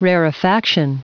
Prononciation du mot rarefaction en anglais (fichier audio)
Prononciation du mot : rarefaction